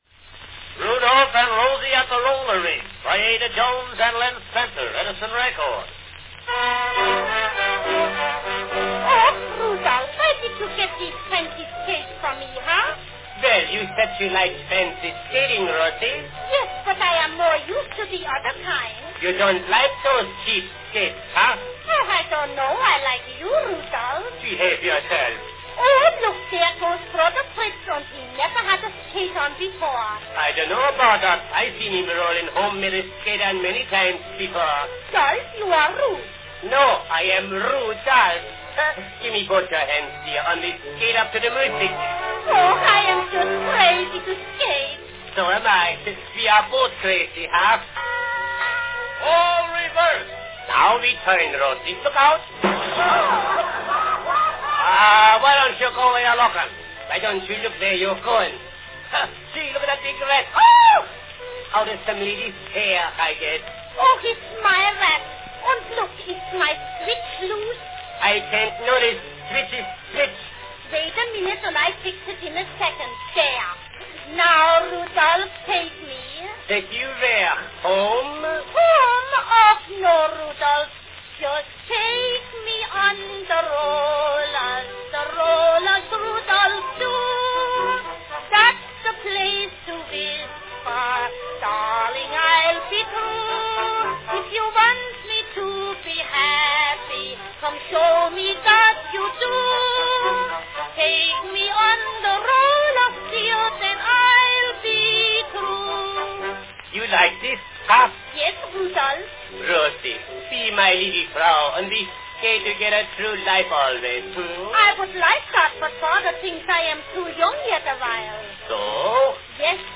Enjoy the comic vaudeville skit, Rudolph and Rosie at the Roller Rink, performed in 1907 by Ada Jones & Len Spencer.
Category Skating scene
Performed by Ada Jones & Len Spencer
Announcement "Rudolph and Rosie at the Roller Rink, by Ada Jones & Len Spencer.  Edison record."
Usually heavy with ethnic overtones, the format was easy to spot: a brief introduction to the two characters, a few (generally corny) jokes, a chorus of a song followed by a joke, marriage proposal, or both.   Good fun squeezed into a 2-minute wax cylinder recording.
Sung on this month's cylinder by Ada Jones (although sung to Rudolph instead of 'Charlie').
No. 9503, "Rudolph and Rosie at the Roller Rink," by Ada Jones and Len Spencer, is a Dutch character sketch, declared to be the best Dutch sketch since "Heinie."